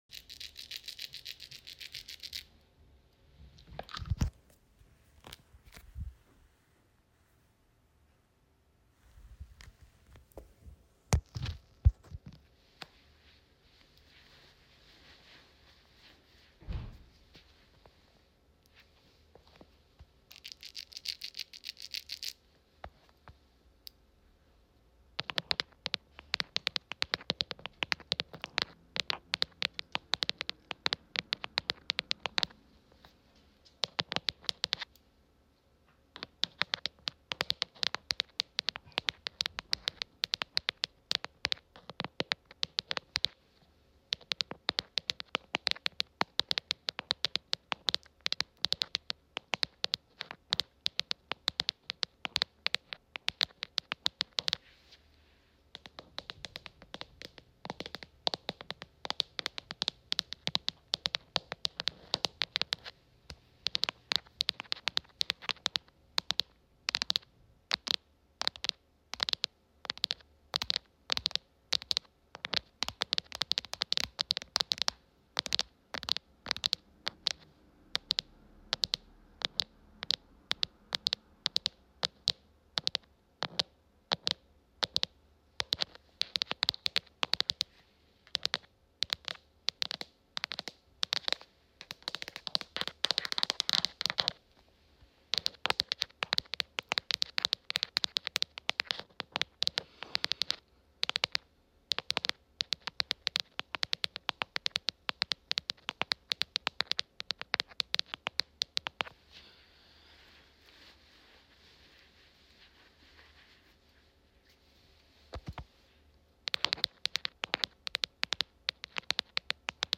Creamy keyboard Mp3 Sound Effect
Creamy keyboard floor tapping part two